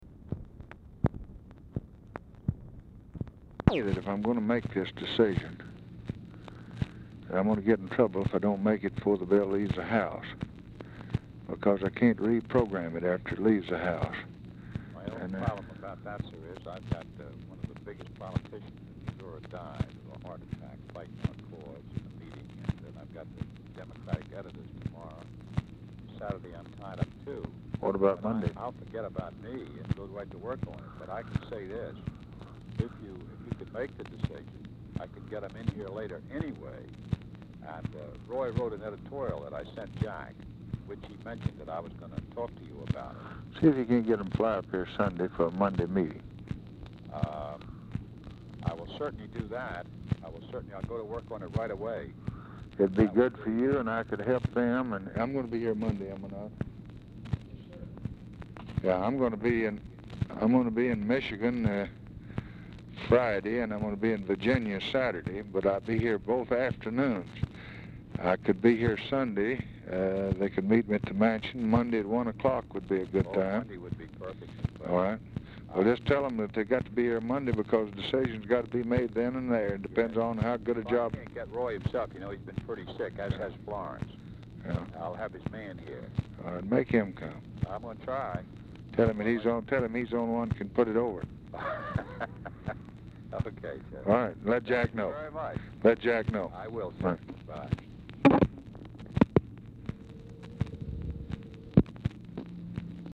Telephone conversation # 3490, sound recording, LBJ and STUART SYMINGTON, 5/21/1964, 1:10PM | Discover LBJ
SYMINGTON SOMETIMES DIFFICULT TO HEAR
Format Dictation belt
Specific Item Type Telephone conversation